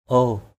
/o:/